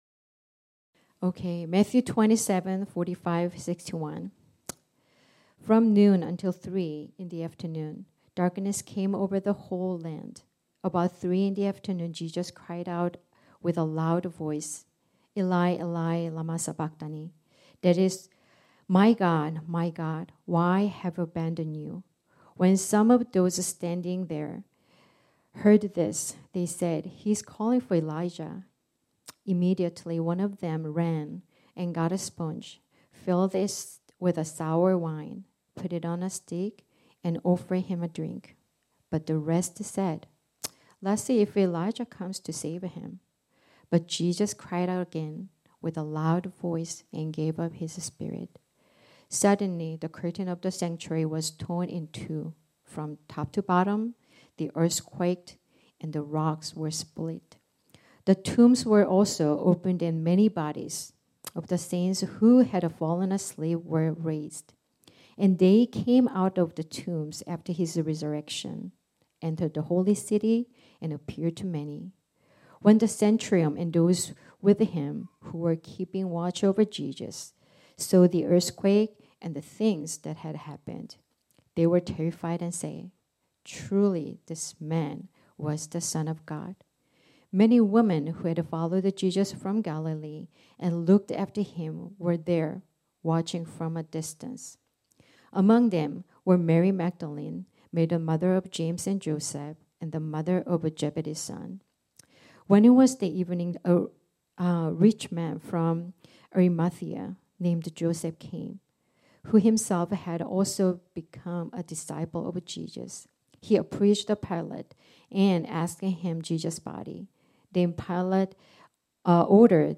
This sermon was originally preached on Sunday, November 24, 2024.